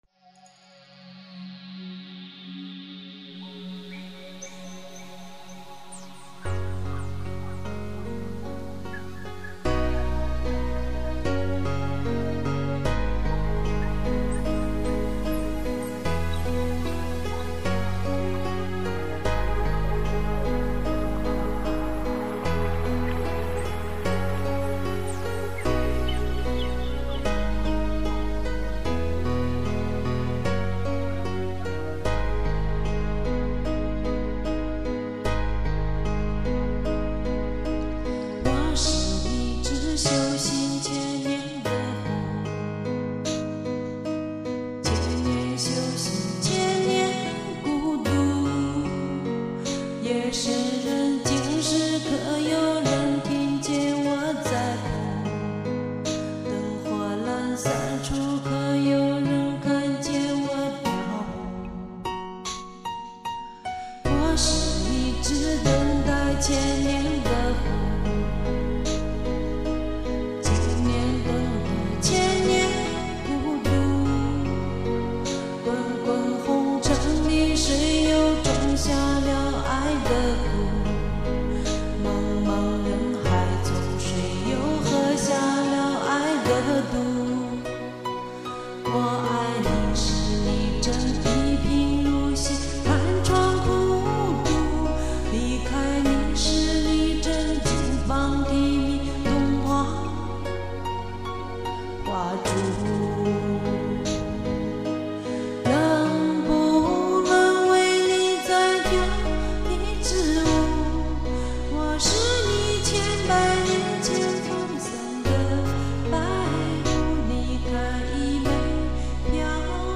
翻唱
嗓子也嚎哑了...